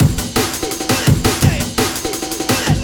cw_amen06_169.wav